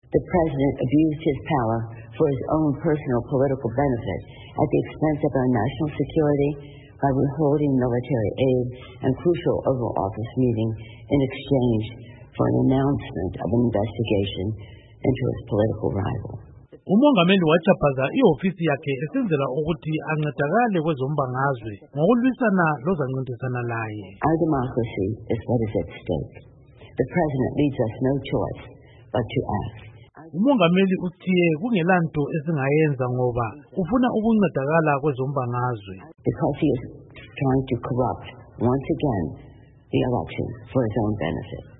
Okwethulwe NguNkosikazi Nancy Pelosi